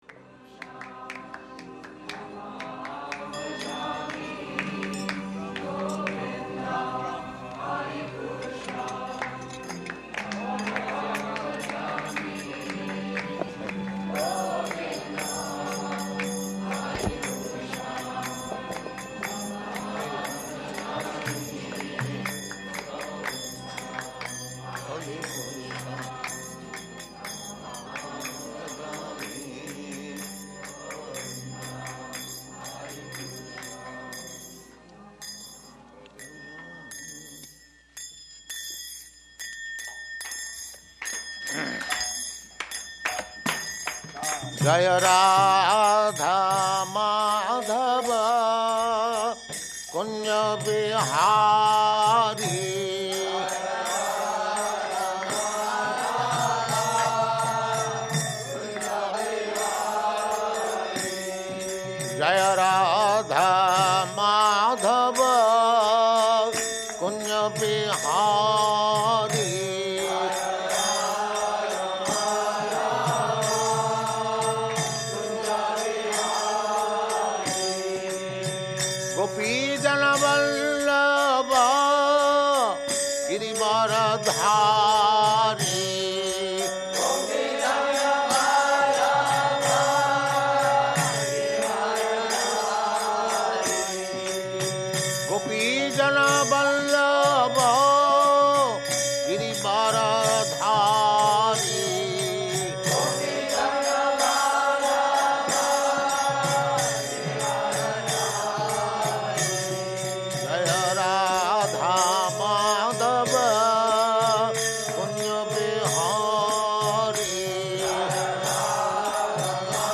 Location: Los Angeles
[ Govindam prayers playing; Prabhupāda singing along]
[microphone adjusted] Do it nicely.